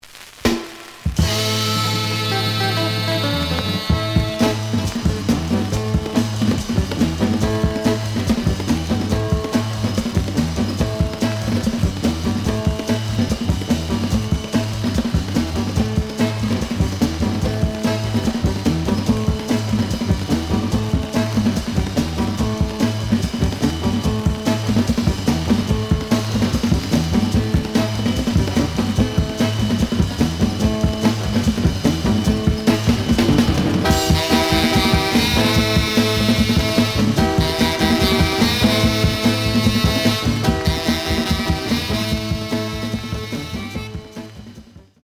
The audio sample is recorded from the actual item.
●Genre: Soul, 60's Soul
Some noise on both sides.